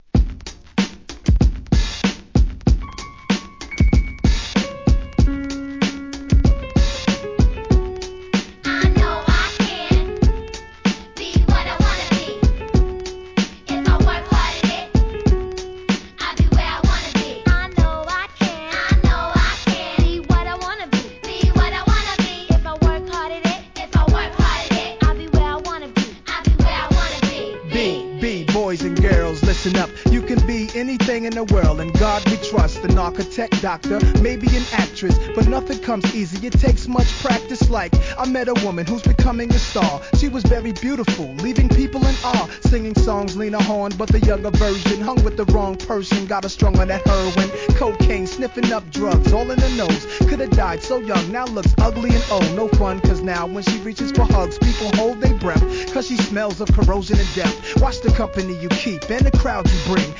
HIP HOP/R&B
「エリーゼのために」を用いたお馴染み2002年大ヒット!